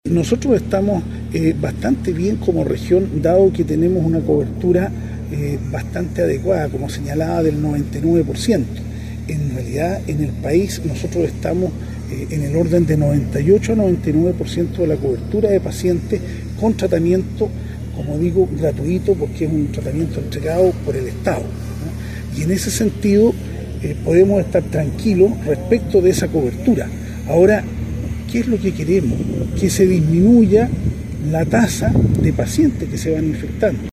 Respecto a la situación de la región en cuanto a esta enfermedad, Barra se mostró optimista. Mencionó que en 2022 se detectaron 259 casos, mientras que en 2023 bajaron a 234.